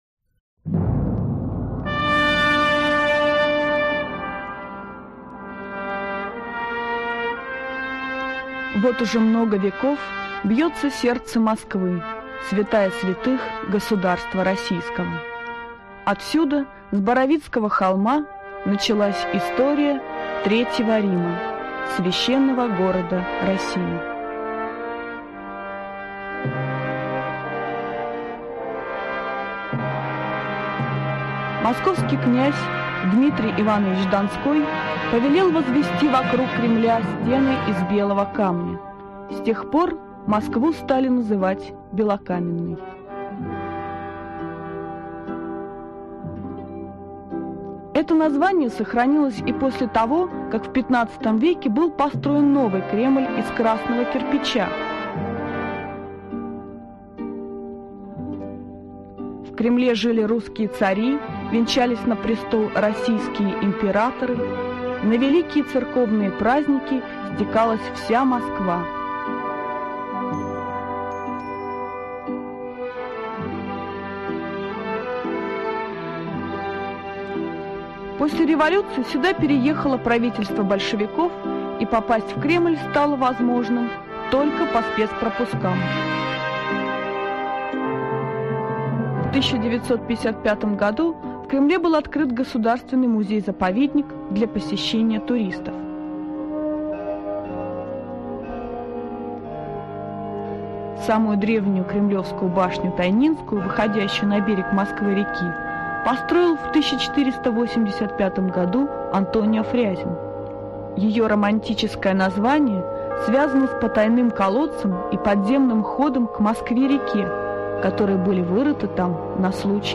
Аудиокнига Москва. Путеводитель | Библиотека аудиокниг